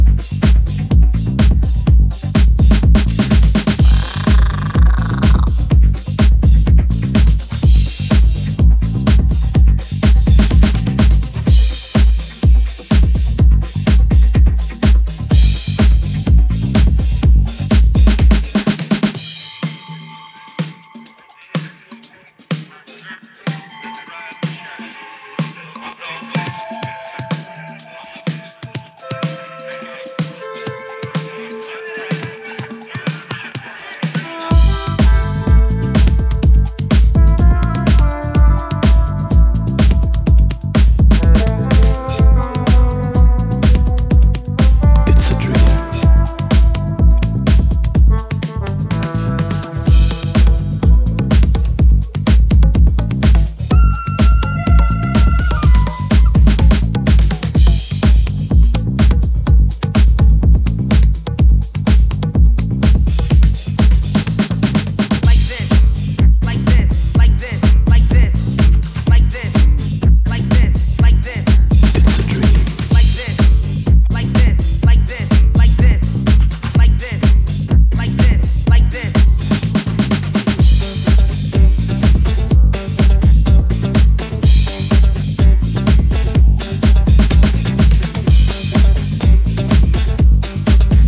ディスコティークなグルーヴをミニマル・ハウスに落とし込んだ機能性抜群のA-1がいきなりキラーチューン。